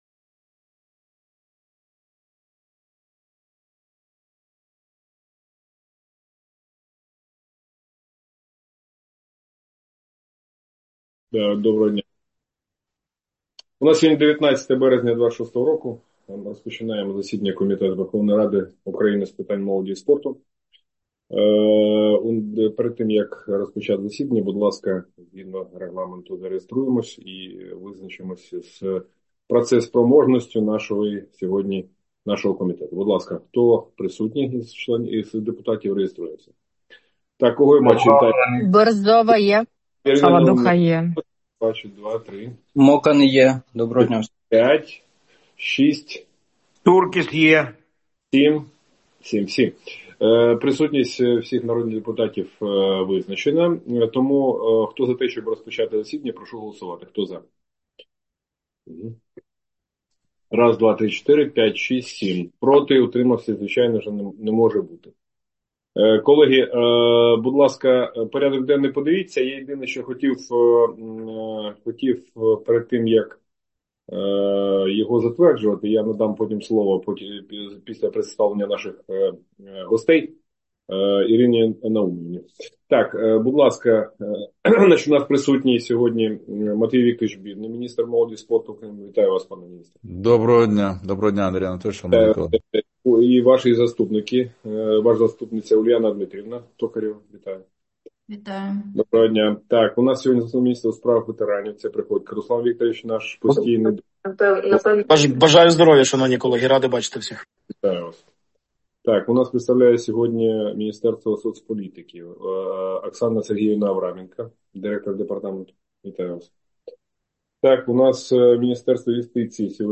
Аудіозаписи засідання Комітету у березні 2026 року